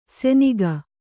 ♪ seniga